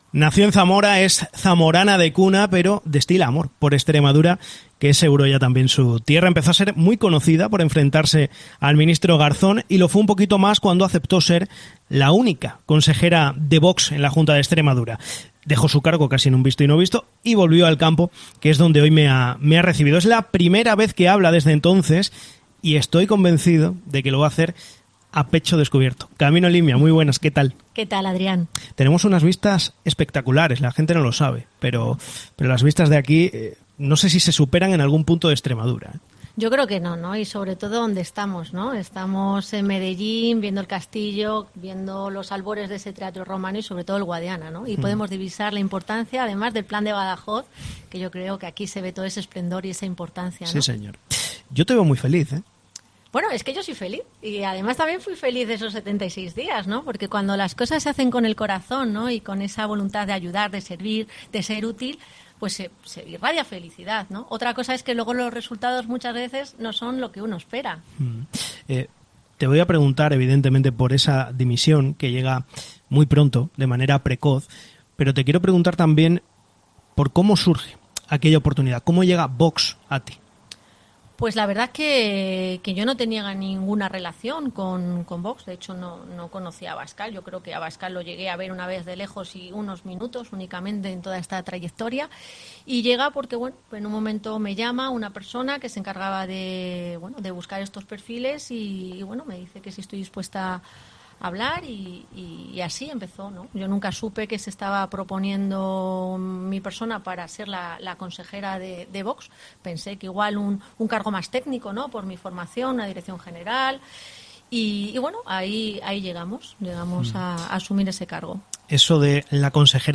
Hoy, cinco meses después, Camino Limia rompe su silencio, para contar su versión, en los micrófonos de COPE Extremadura . En una charla de veinte minutos, Limia logra responder a todo.